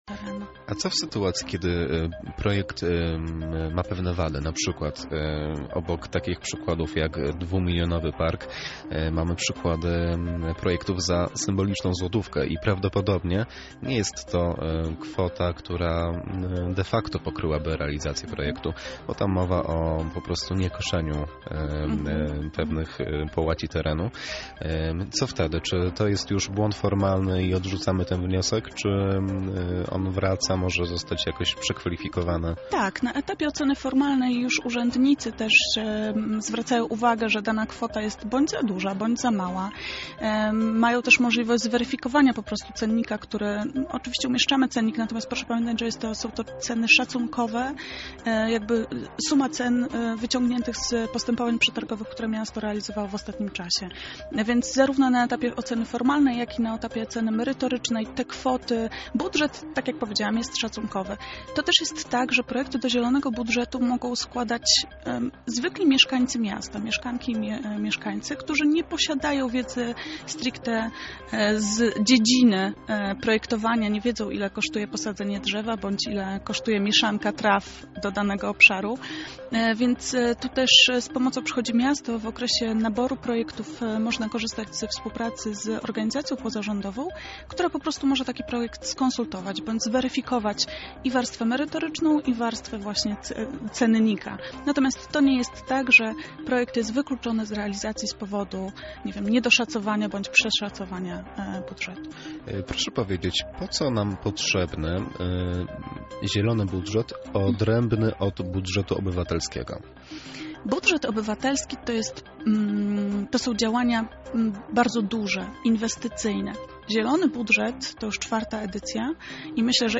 Pełna rozmowa na temat Zielonego Budżetu dostępna poniżej: